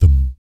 Index of /90_sSampleCDs/ILIO - Vocal Planet VOL-3 - Jazz & FX/Partition B/4 BASS THUMS